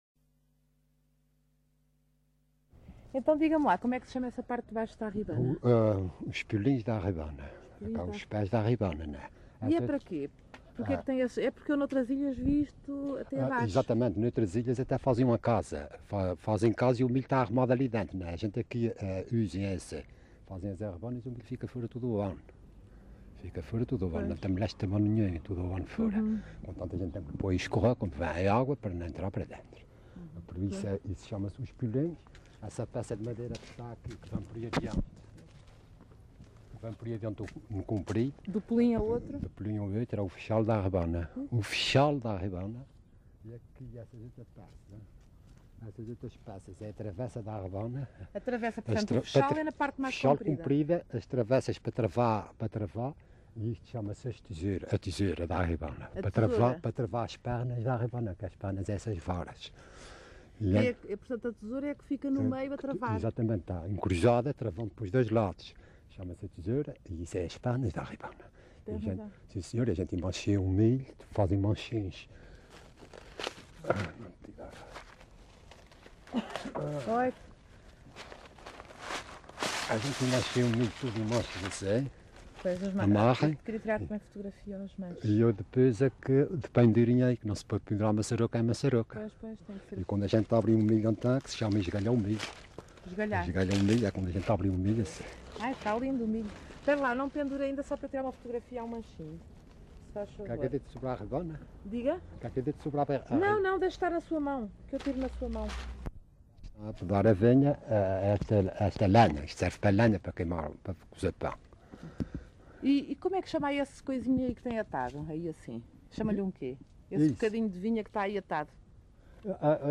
LocalidadePonta Garça (Vila Franca do Campo, Ponta Delgada)